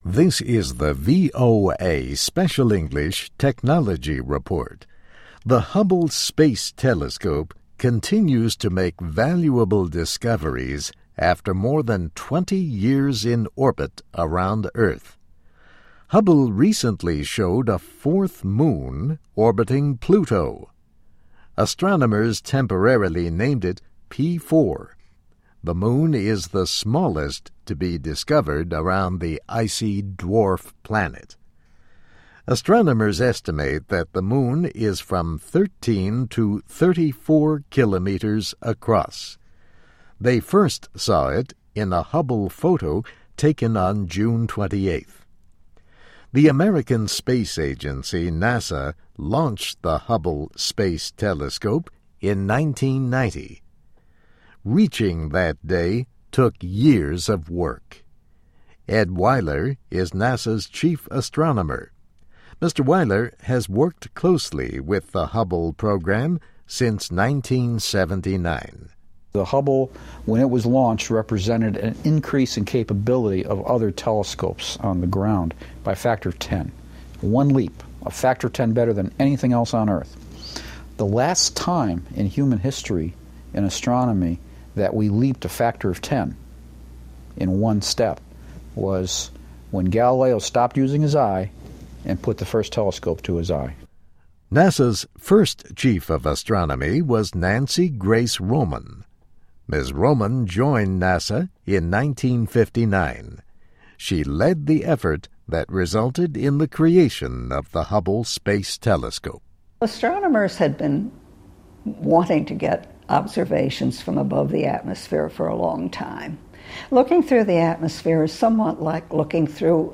Astronomers Still Look to Hubble Space Telescope (VOA Special English 2011-08-28)